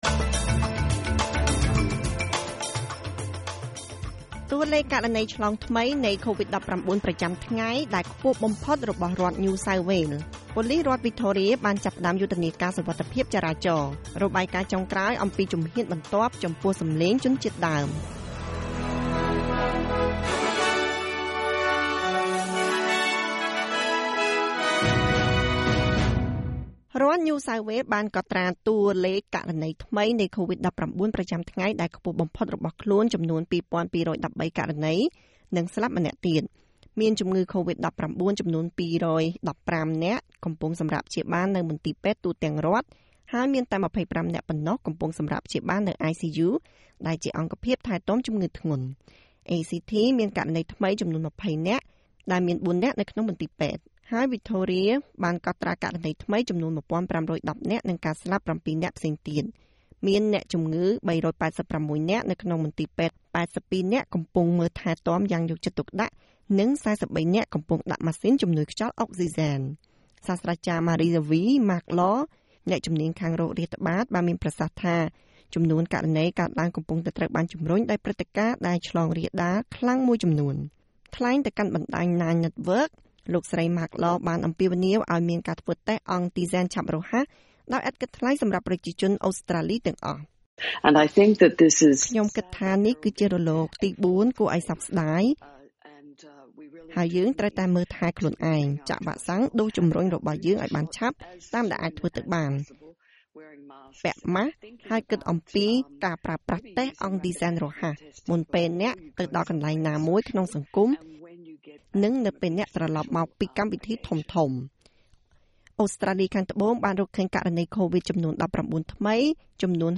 នាទីព័ត៌មានរបស់SBSខ្មែរ សម្រាប់ថ្ងៃពុធ ទី១៧ ខែធ្នូ ឆ្នាំ២០២១